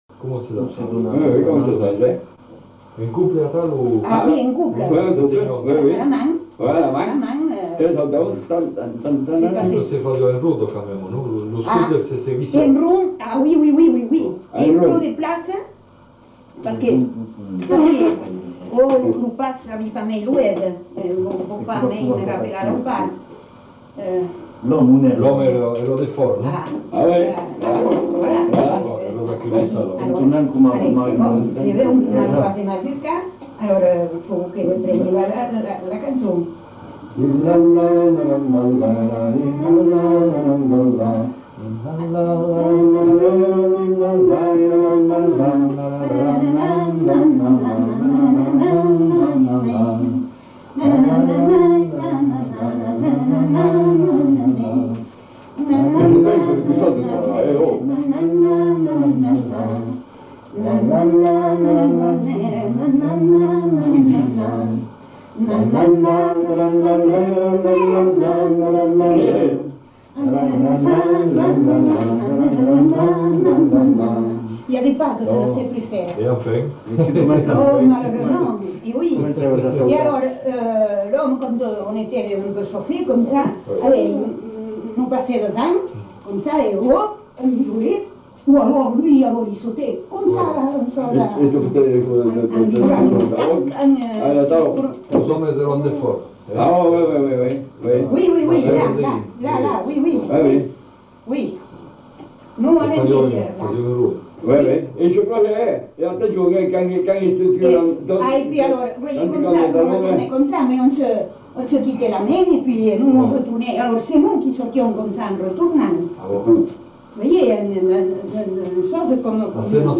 Aire culturelle : Marmandais gascon
Lieu : Tonneins
Genre : témoignage thématique